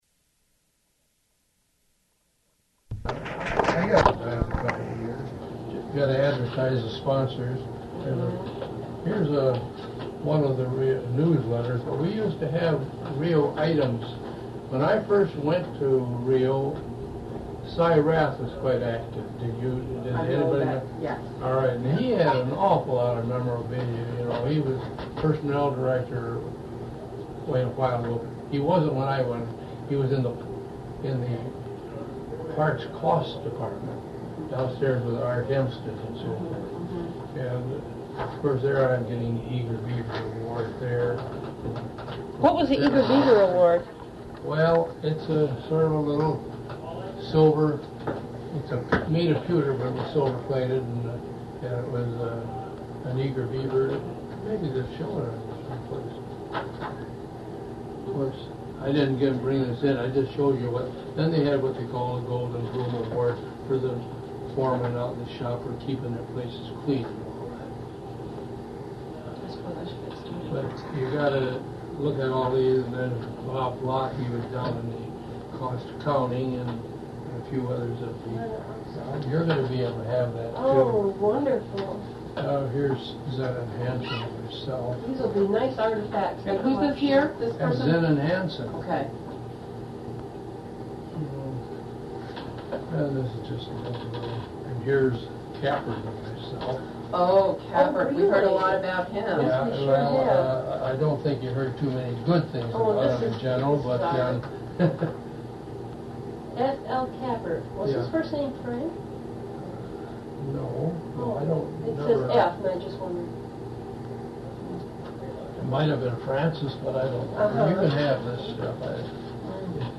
Recorded as part of the REO Memories oral history project.